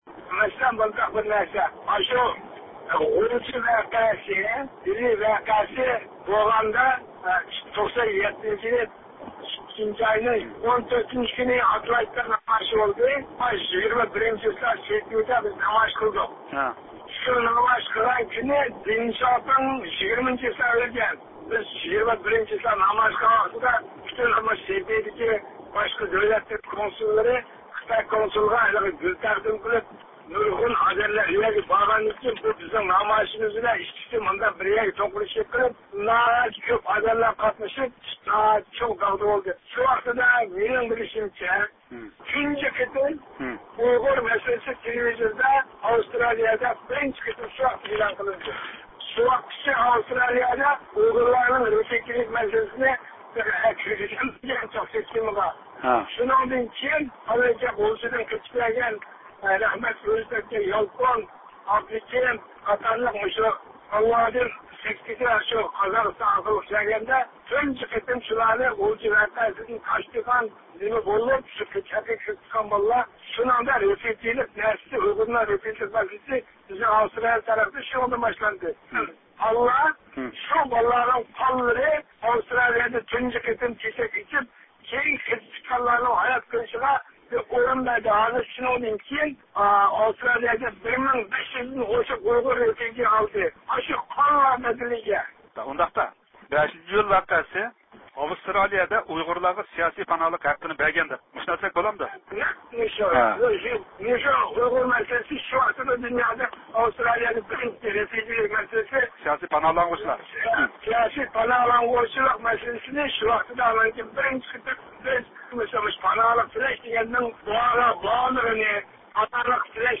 مۇخبىرىمىزنىڭ بۈگۈنكى زىيارىتىدە